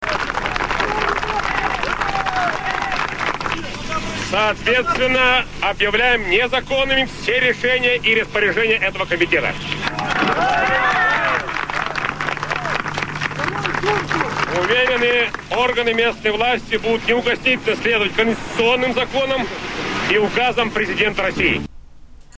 Речь у здания Верховного Совета РСФСР (19.08.1991) | Ельцин Центр
Perhaps your browser cannot display it, or maybe it did not initialize correctly. rech-prezidenta-rsfsr-borisa-nikolaevicha-eltsina.mp3 Речь Президента РСФСР Бориса Николаевича Ельцина во время выступления у здания Верховного Совета РСФСР. 19 августа 1991 года. Russian President B. Yeltsin is speaking outside the building of the RSFSR Supreme Soviet.
rech-prezidenta-rsfsr-borisa-nikolaevicha-eltsina.mp3